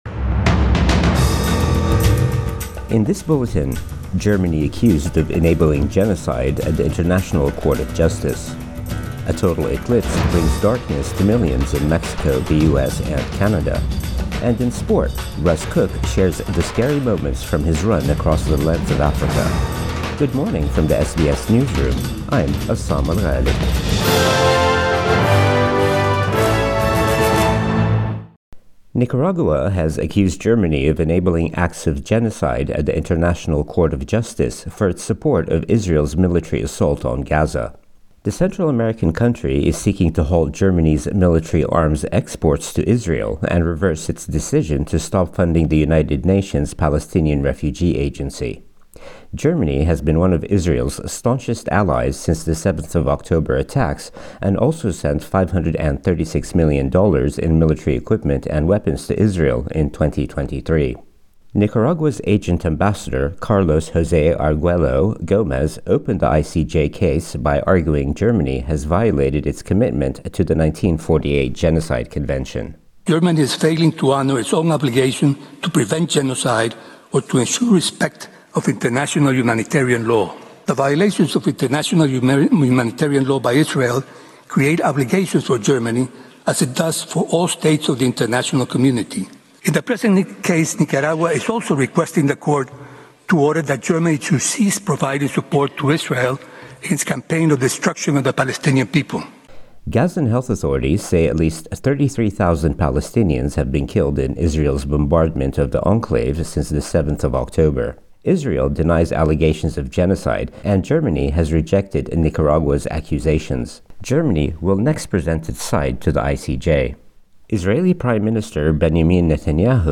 Morning News Bulletin 9 April 2024